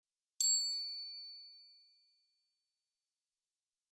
Category: Reactions Soundboard